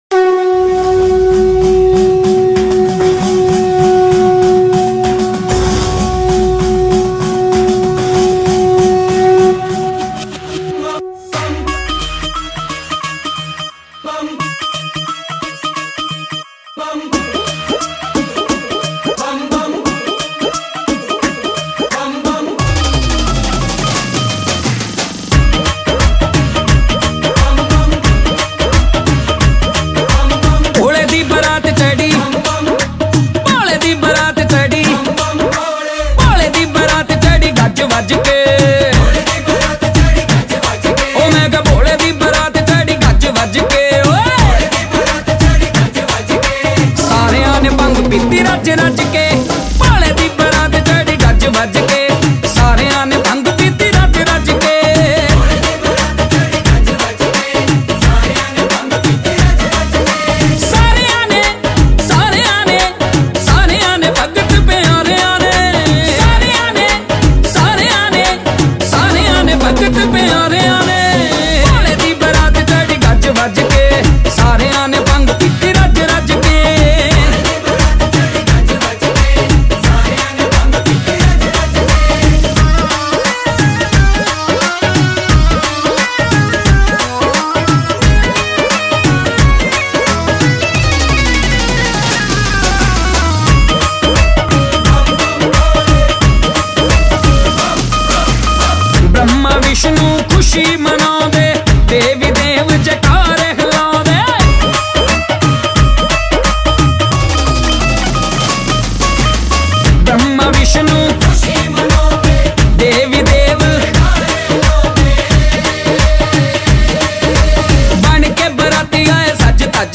Latest Punjabi shiv bhajans